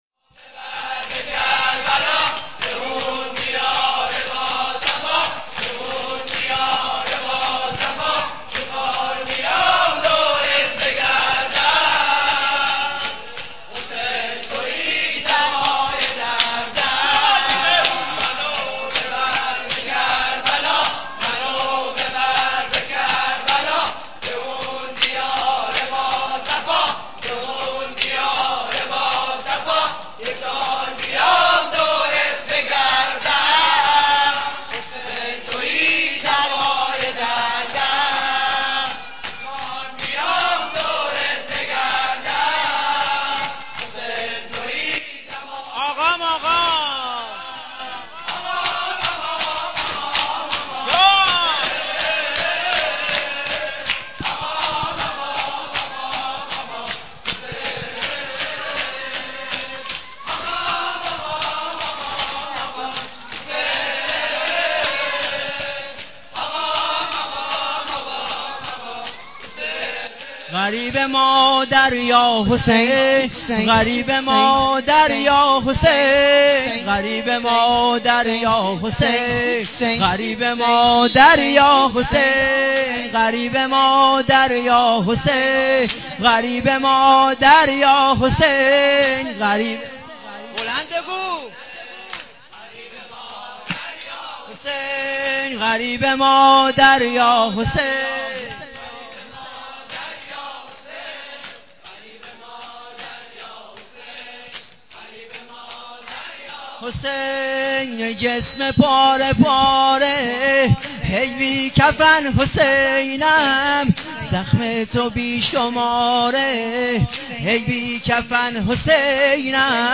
نوحه سینه زنی محرم ۹۵